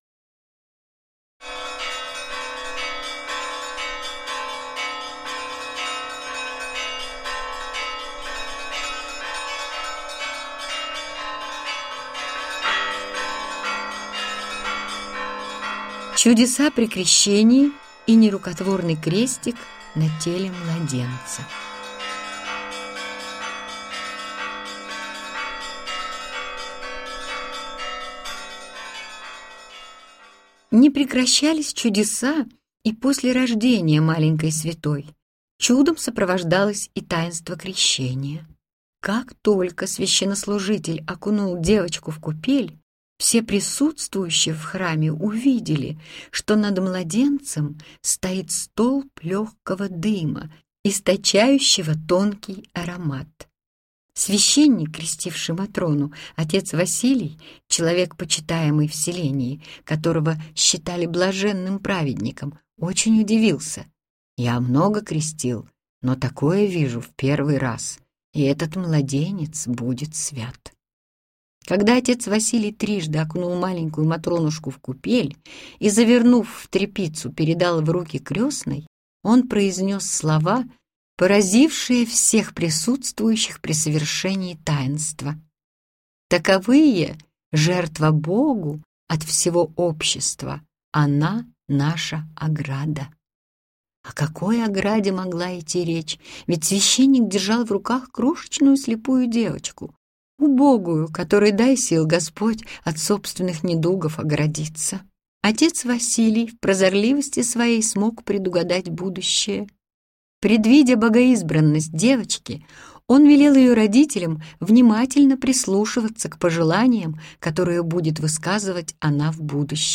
Аудиокнига Вам поможет святая блаженная Матрона Московская | Библиотека аудиокниг